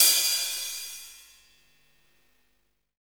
HAT FUNK H0V.wav